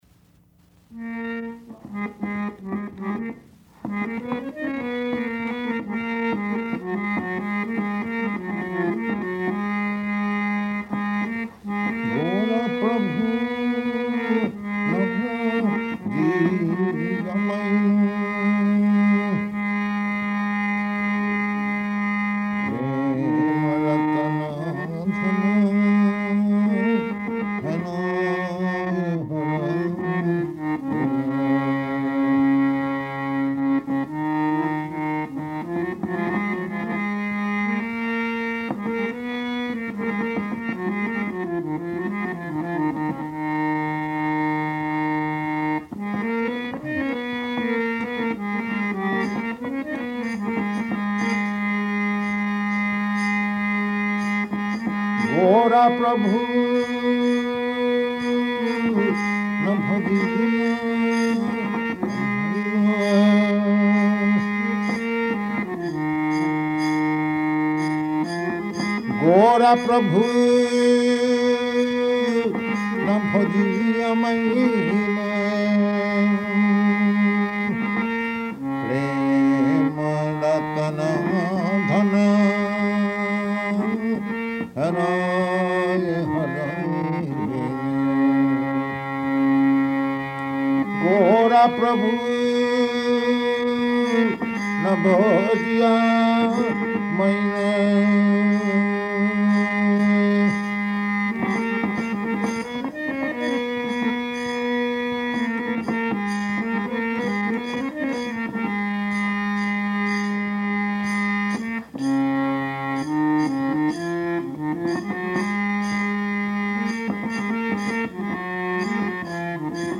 Bhajan & Purport to Gaura Pahū
Type: Purport
Location: Los Angeles